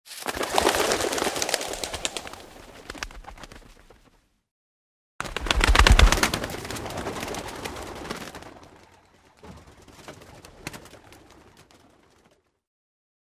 Звук неожиданного взлета птиц